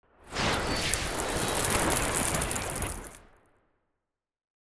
Free Frost Mage - SFX
freezing_gush_08.wav